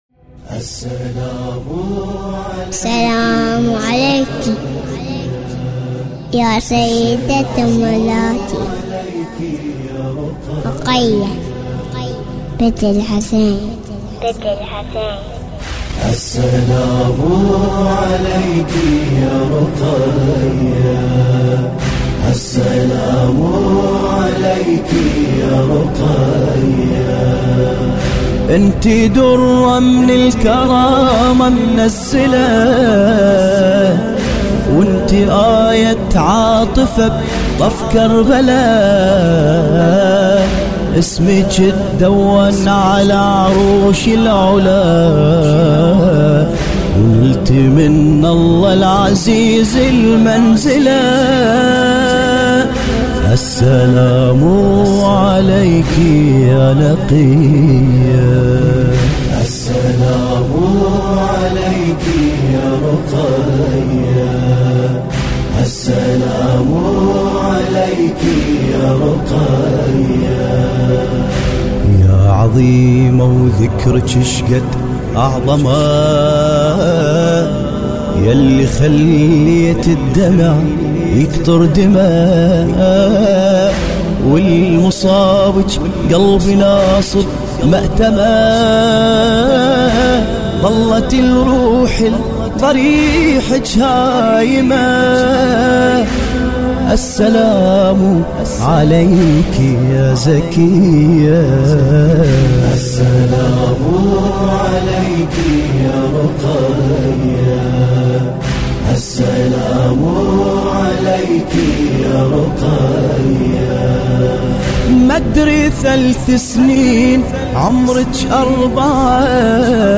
مراثي رقية (س)